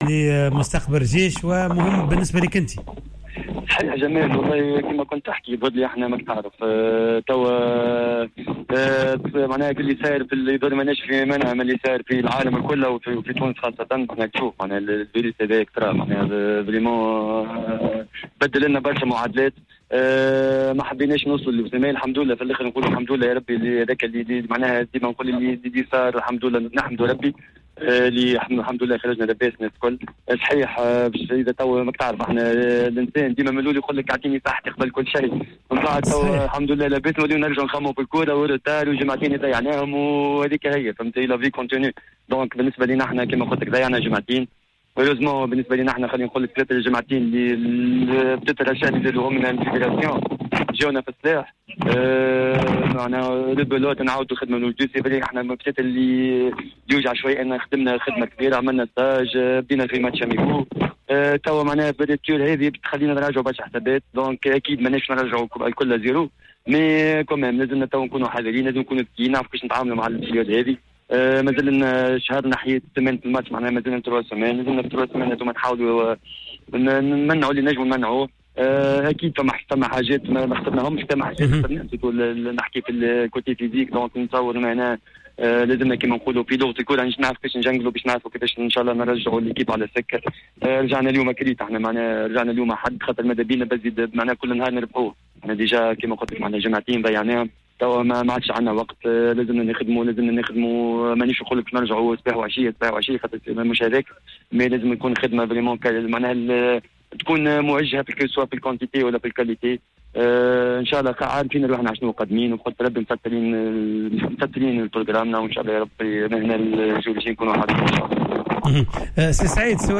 تصريح خاص براديو جوهرة